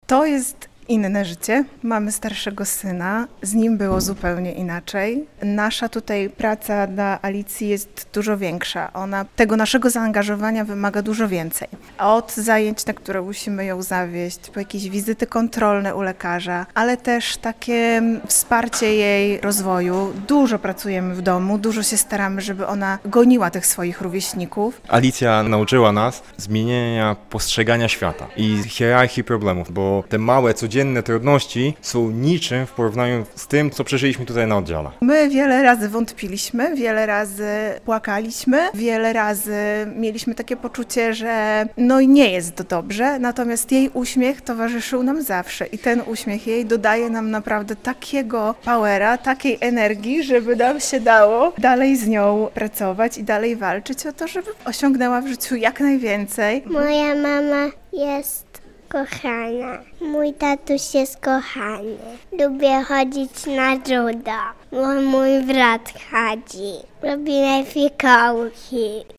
17 listopada to Światowy Dzień Wcześniaka - relacja